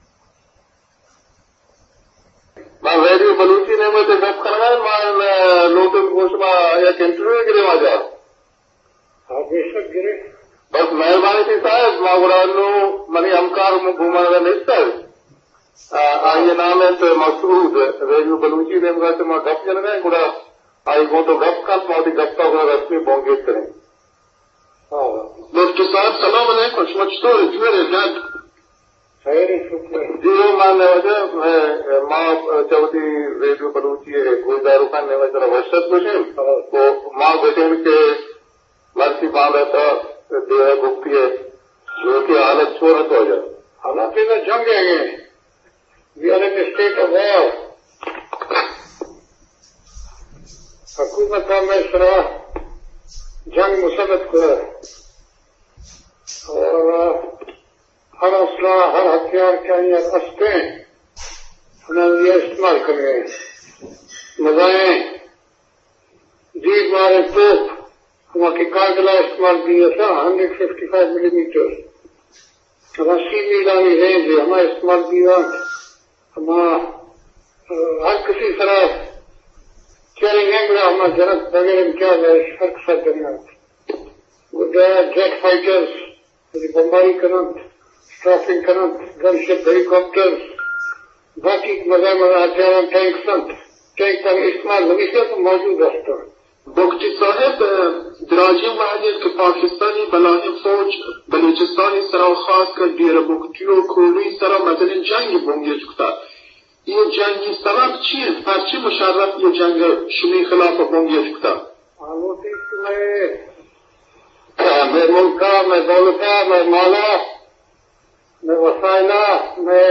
Shaheed-Nawab-Akbar-Bugti-interview-with-Radio-Balochi-2006.mp3